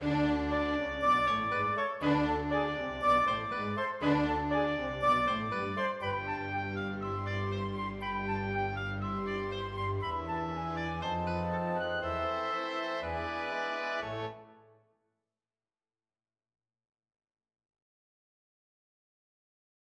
Каждое повторение нисходящей фразы изменяется по одной ноте, что приводит к переходу музыки из ля мажора через ля минор и далее через аккорд соль-септаккорда к отдалённой тональности до мажор, а затем обратно к ля мажору.